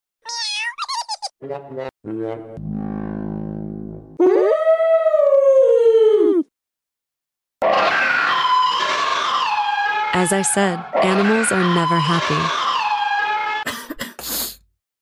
Cat crying 😭🐱 sound effects free download